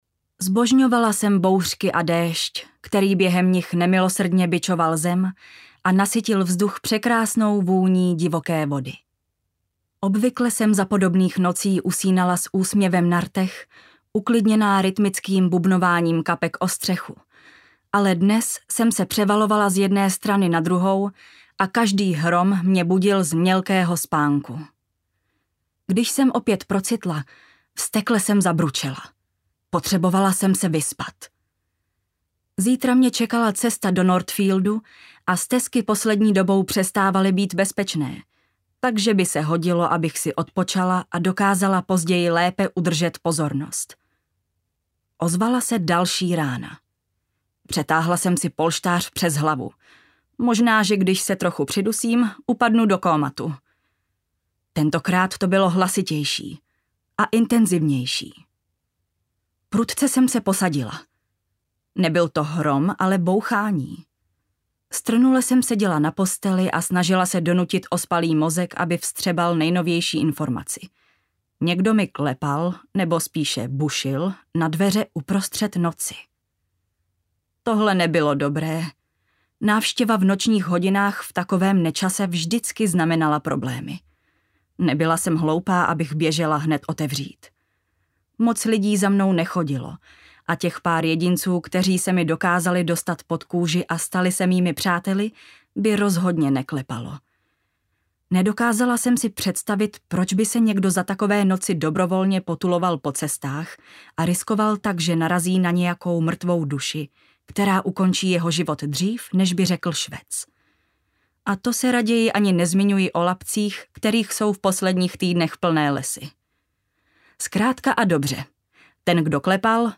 Woodhill audiokniha
Ukázka z knihy